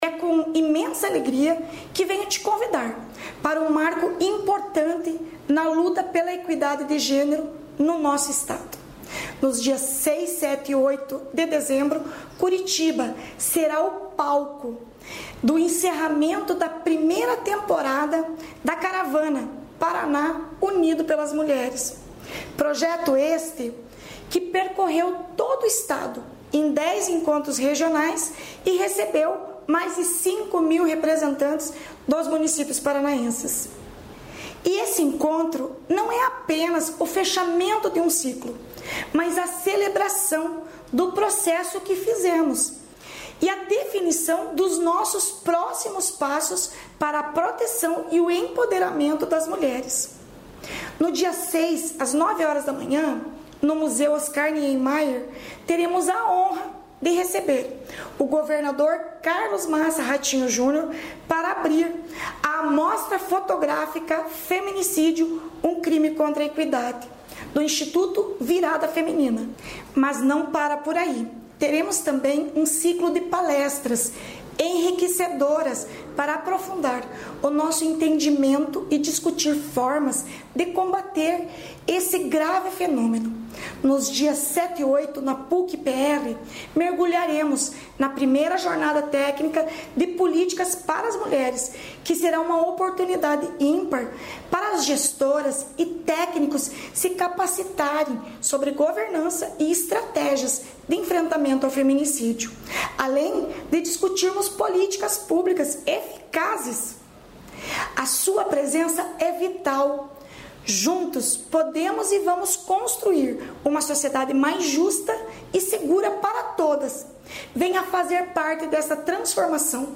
Sonora da secretária da Mulher, Igualdade Racial e Pessoa Idosa, Leandre Dal Ponte, sobre o programa Caravana Paraná Unido Pelas Mulheres | Governo do Estado do Paraná
Sonora da secretária da Mulher, Igualdade Racial e Pessoa Idosa, Leandre Dal Ponte, sobre o programa Caravana Paraná Unido Pelas Mulheres